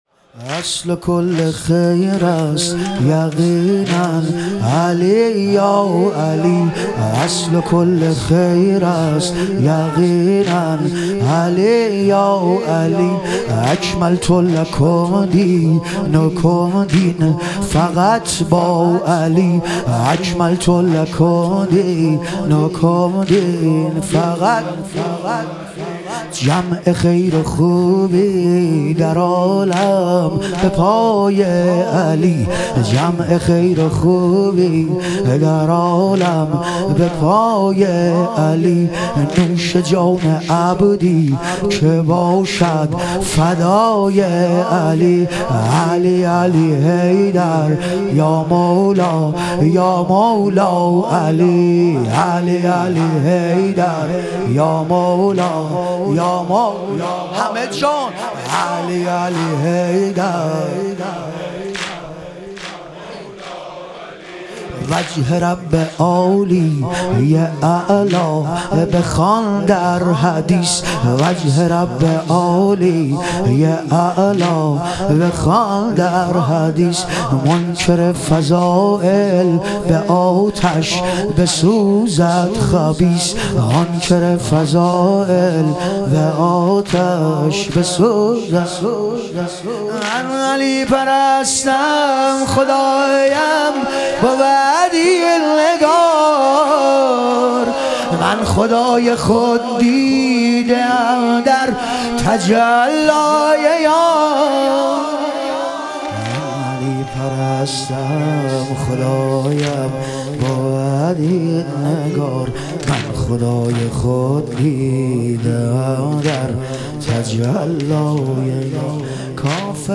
شهادت حضرت جعفرطیار علیه السلام - واحد